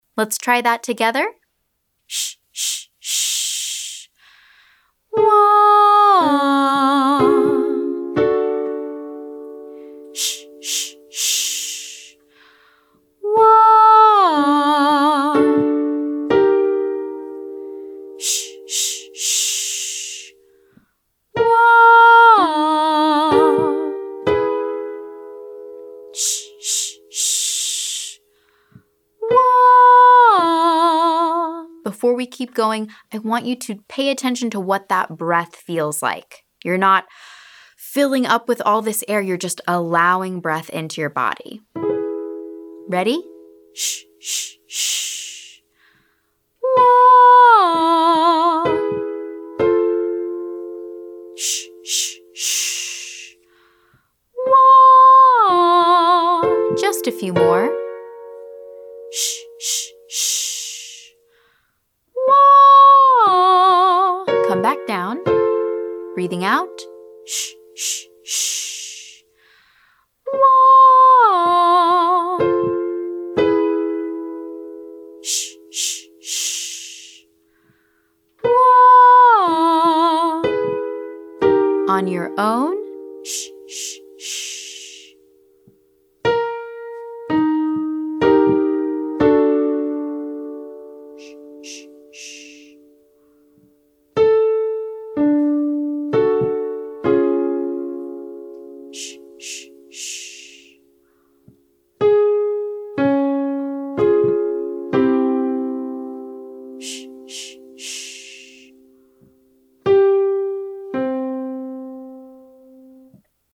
Exercise 1: Breath Release Inhale to Wah  5-1
Then sing this simple pattern.